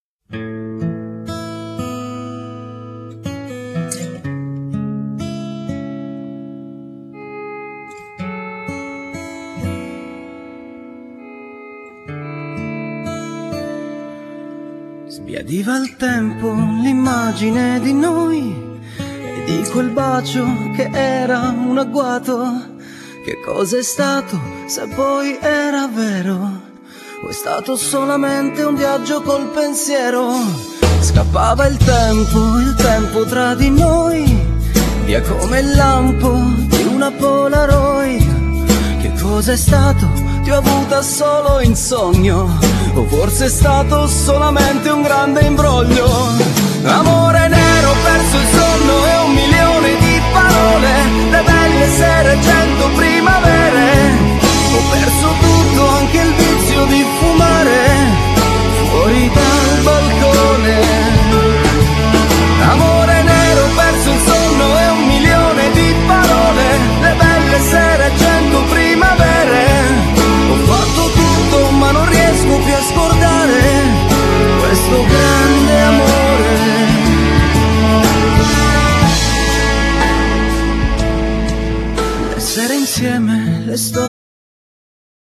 Genere : Pop
l’accattivante melodia italiana